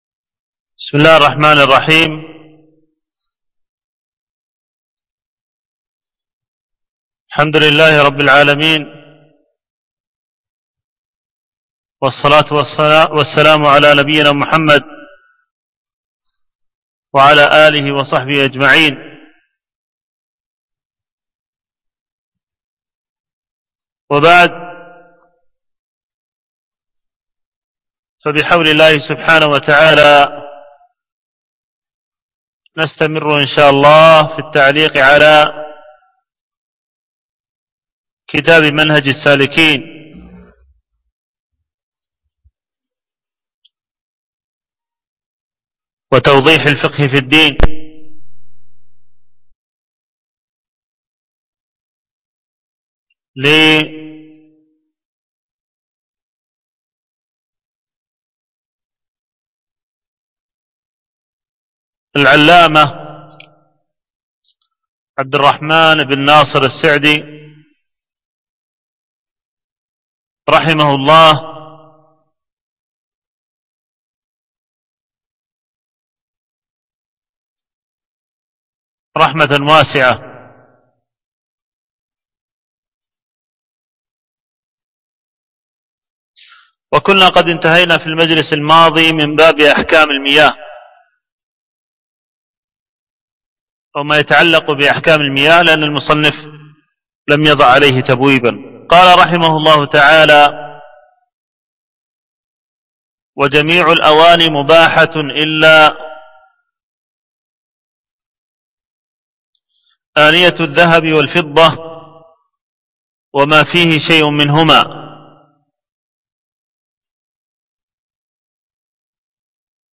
الدرس الرابع الألبوم